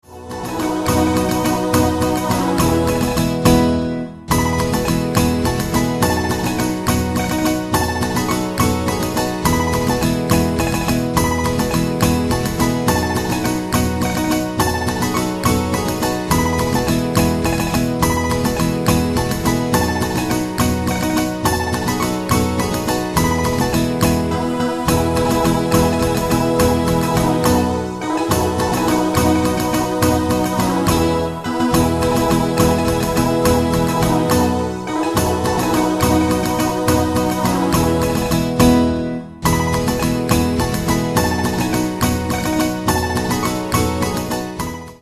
Paso Doble Midi File Backing Tracks.